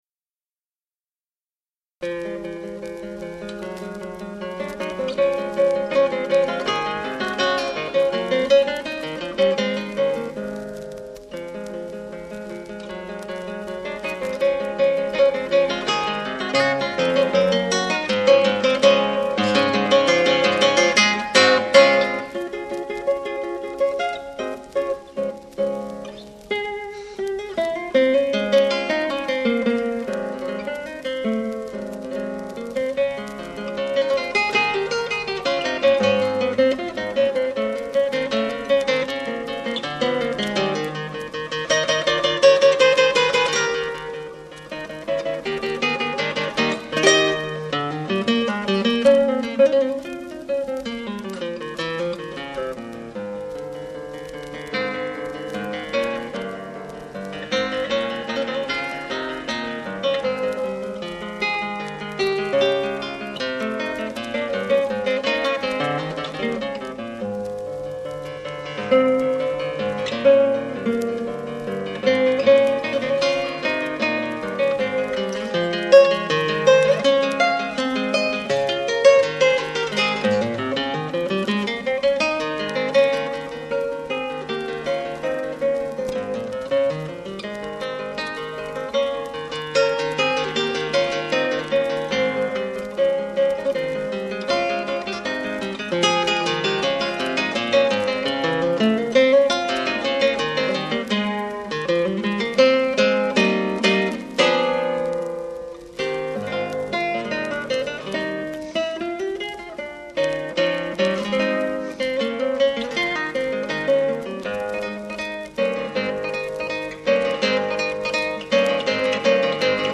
IN E MINOR